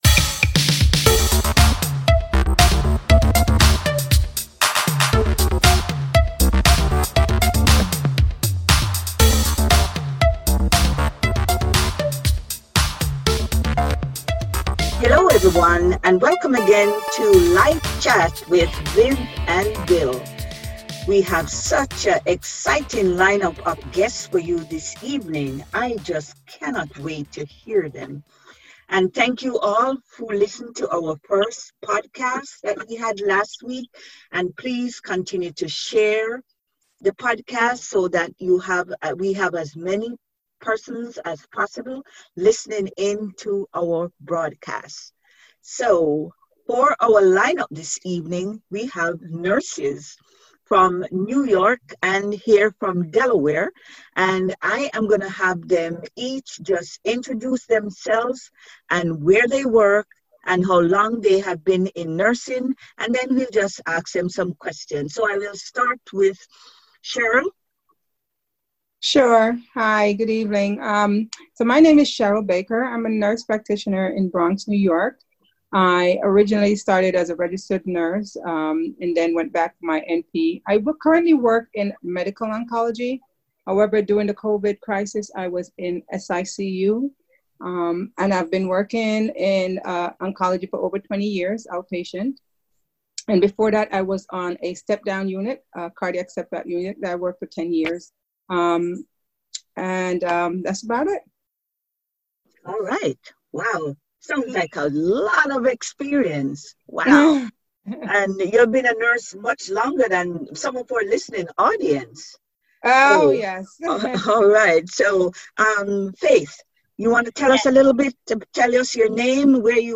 We chatted with three nurses on the frontline discussing their “new norm,” working with COVID-19 patients, their families and how the coronavirus has affected them.